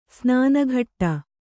♪ snāna ghaṭṭa